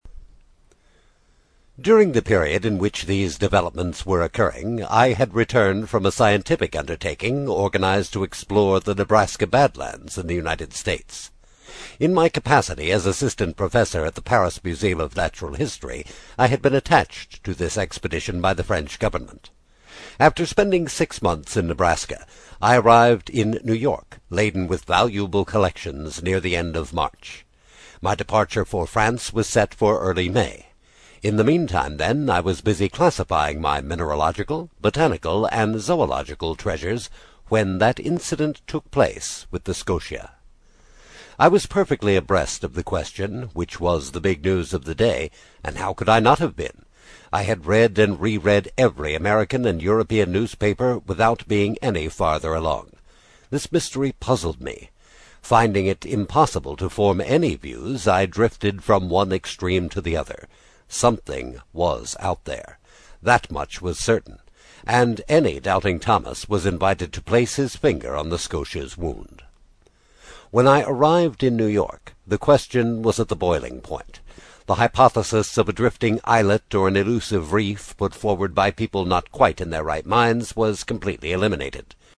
在线英语听力室英语听书《海底两万里》第12期 第2章 正与反(1)的听力文件下载,《海底两万里》中英双语有声读物附MP3下载